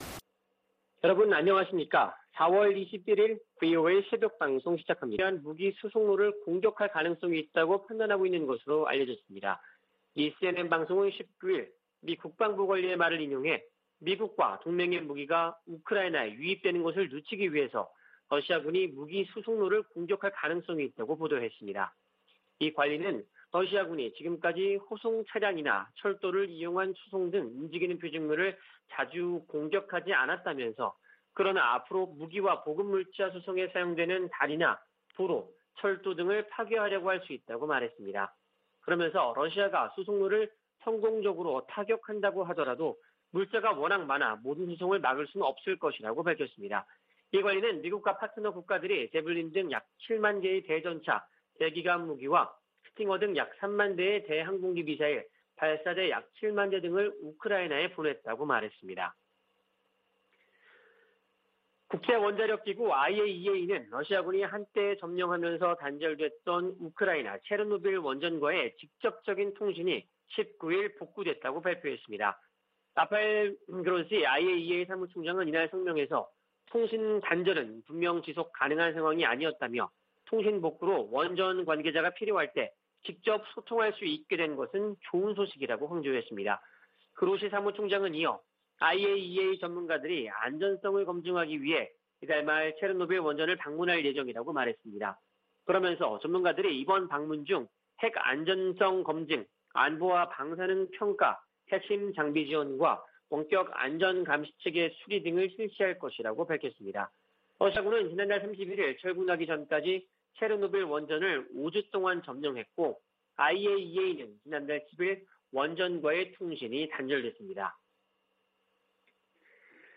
VOA 한국어 '출발 뉴스 쇼', 2022년 4월 21일 방송입니다. 미 국무부는 북한이 도발을 계속하면 상응 조치를 이어갈 것이라고 경고했습니다.